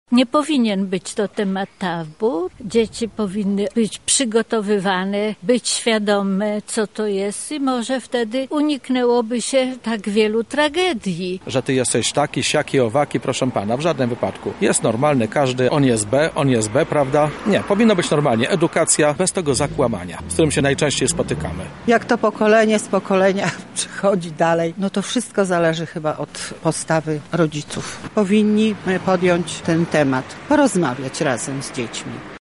[SONDA] Czy seks to temat tabu?
Zapytaliśmy grupę seniorów o to, czy słusznie unikamy tej tematyki w rozmowach: